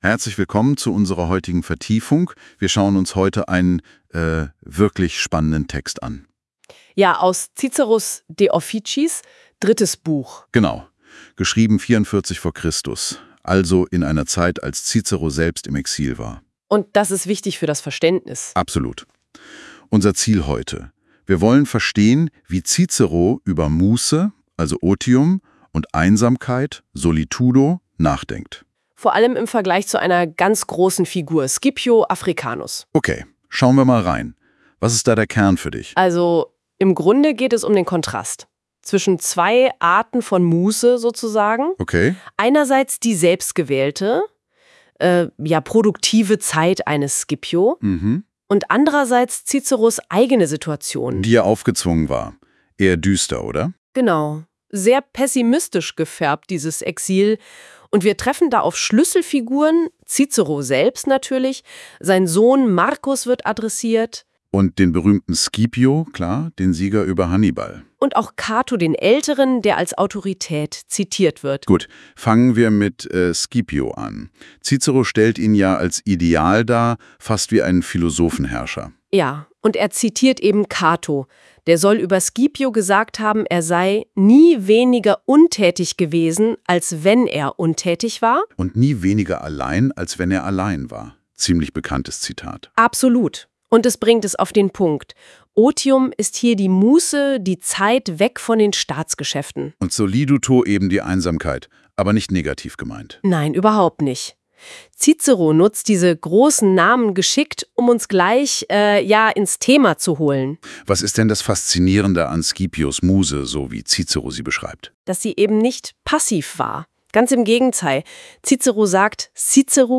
Die Auswahl der Originaltexte entstammt der Sammlung der alten Staatsexamenklausuren. Erstellt mit Google Notebook LM